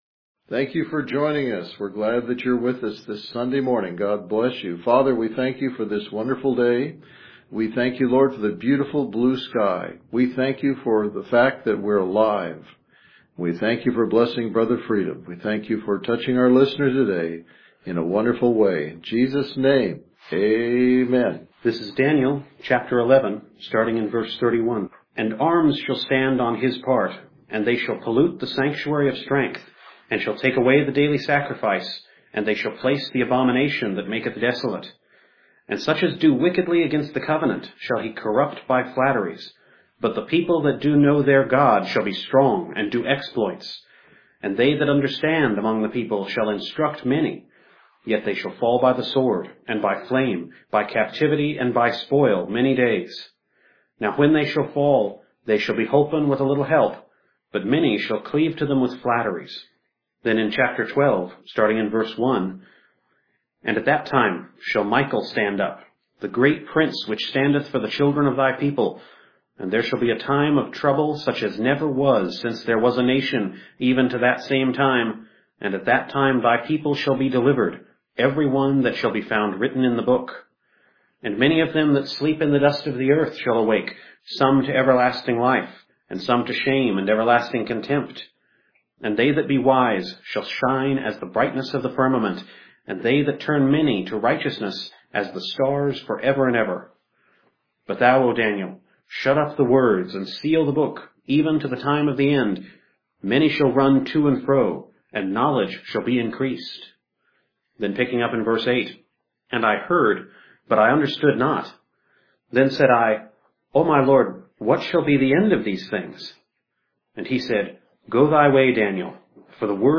You can hear voices of staff folks out in the hall in the background.